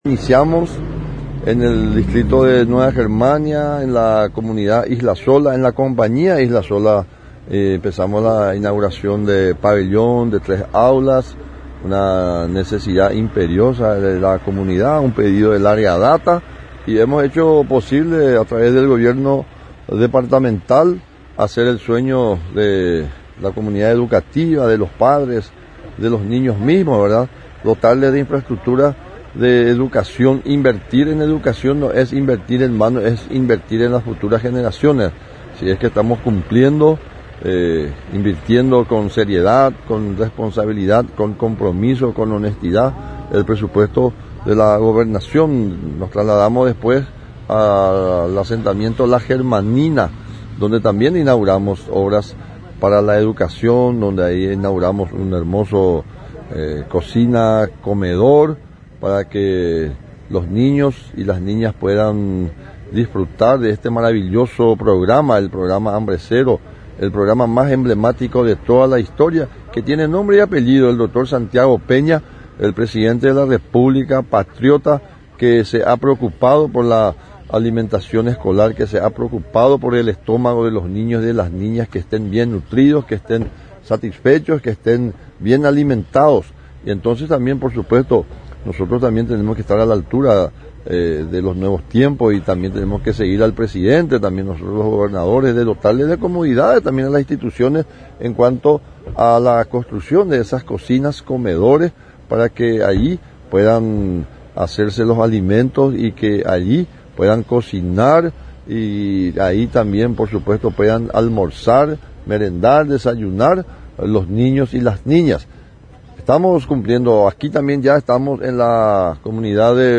NOTA: FREDDY DECCLESIIS-GOBERNADOR DE SAN PEDRO.